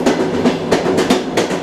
Factorio-Paranoidal_mod / newtrainsfx / sfx / train / rail / stuk_2.ogg
stuk_2.ogg